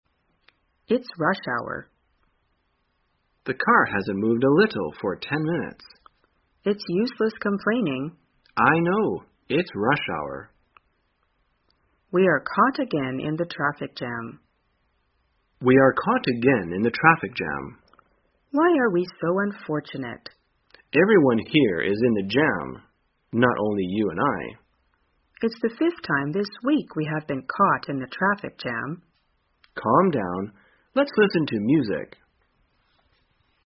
在线英语听力室生活口语天天说 第319期:怎样谈论塞车的听力文件下载,《生活口语天天说》栏目将日常生活中最常用到的口语句型进行收集和重点讲解。真人发音配字幕帮助英语爱好者们练习听力并进行口语跟读。